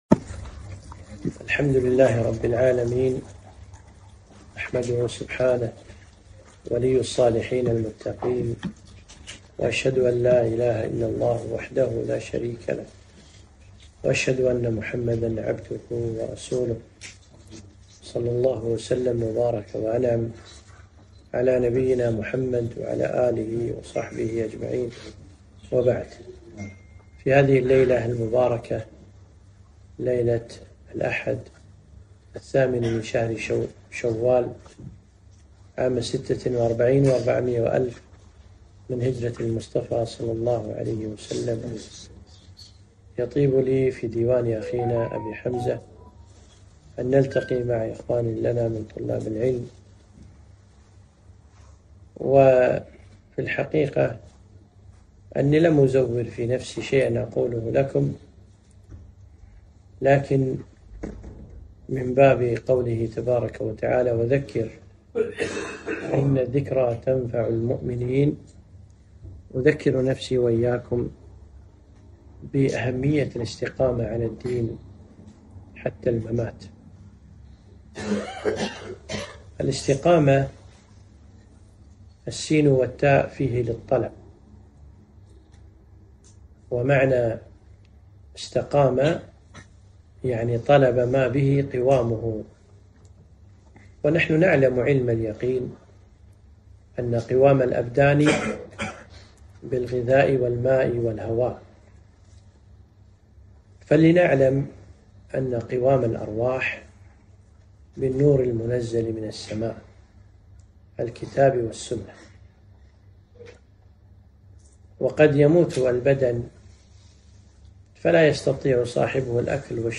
كلمة - الاستقامة